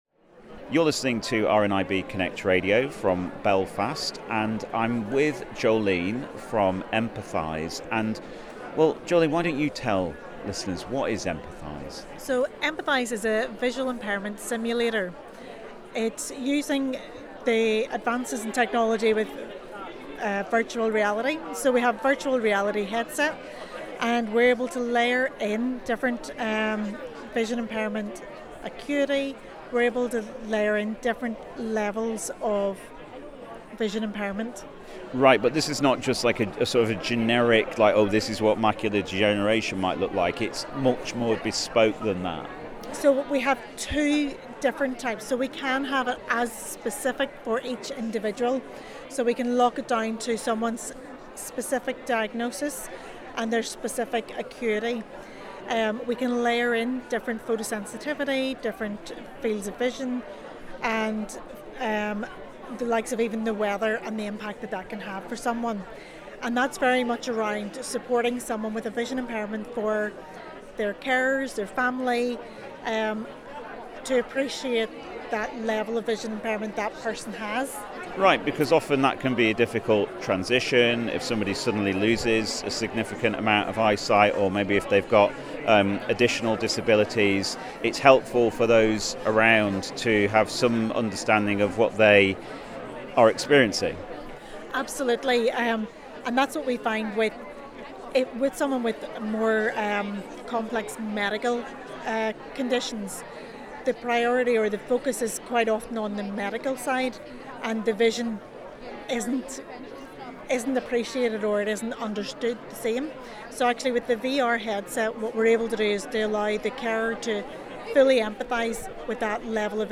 Empatheyes - RNIB Technology For Life Fair Belfast